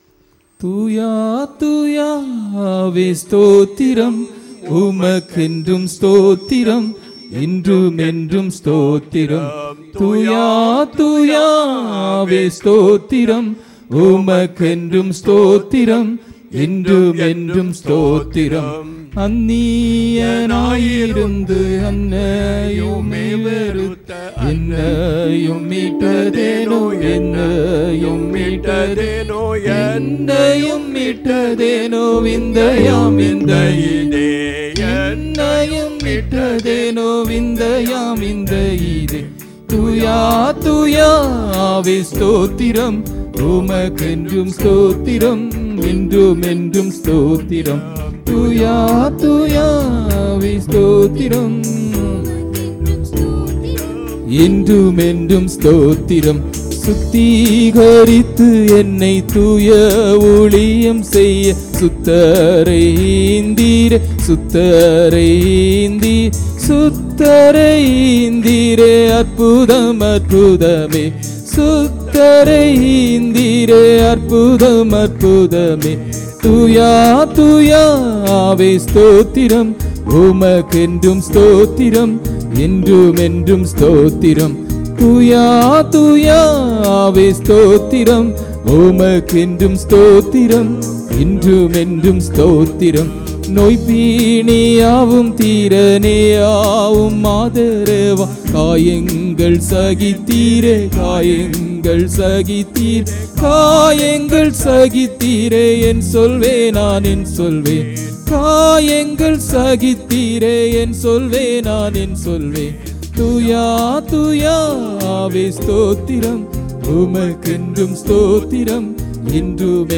23 Nov 2025 Sunday Morning Service – Christ King Faith Mission
Here are some of the key points from the sermon, with relevant Bible verses that align with the themes discussed: